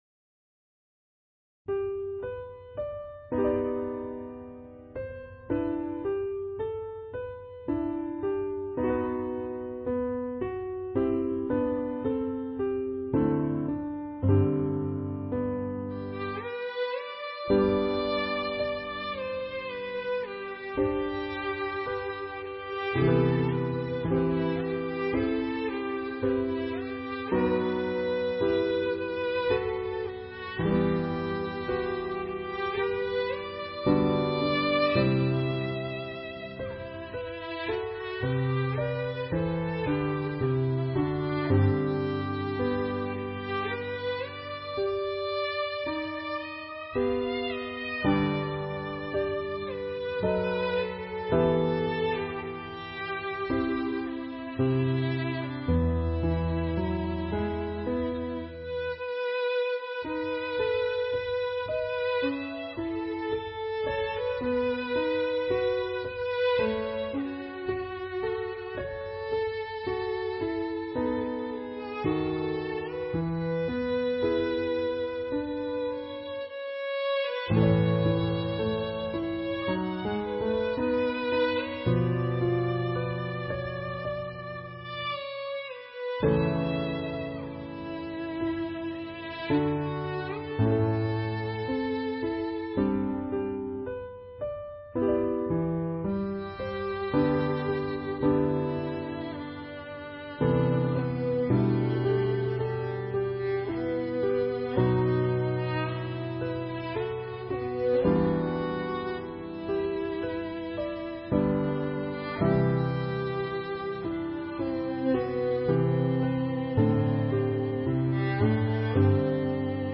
Voicing/Instrumentation: Violin Solo We also have other 12 arrangements of " This Is the Christ ".